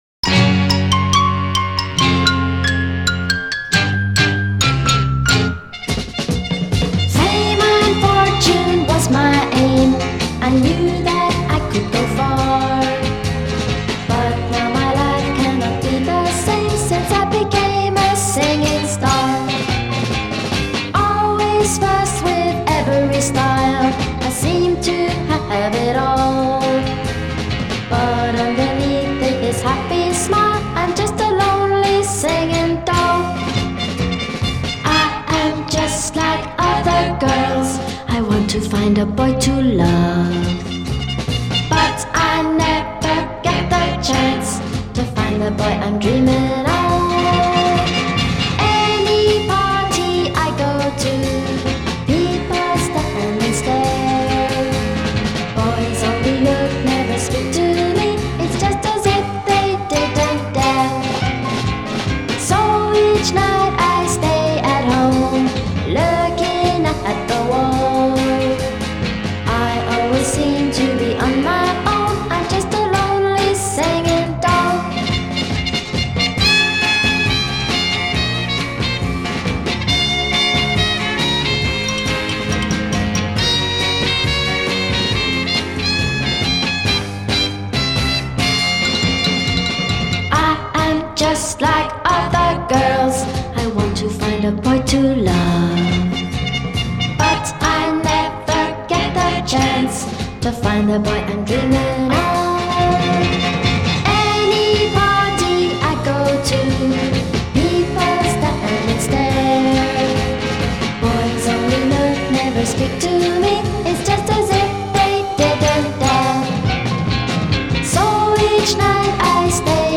English 60s singer